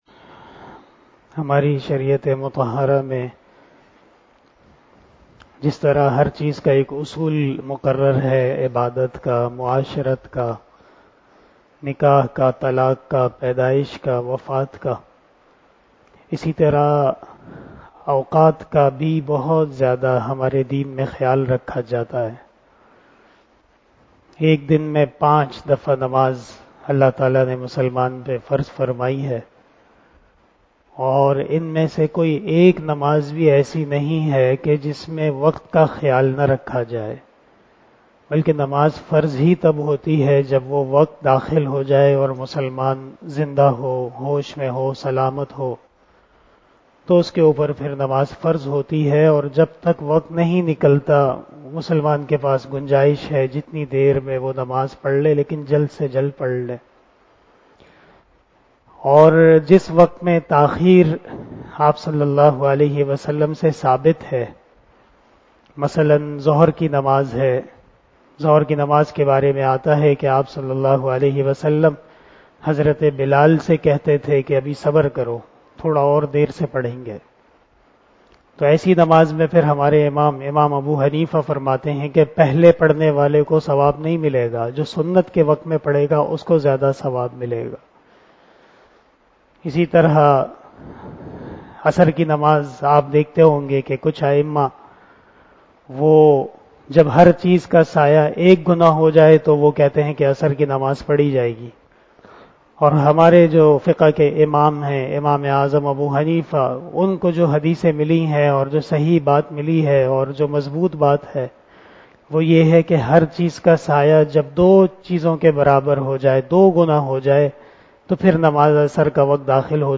052 After Asar Namaz Bayan 22 April 2022 ( 20 Ramadan 1443HJ) Friday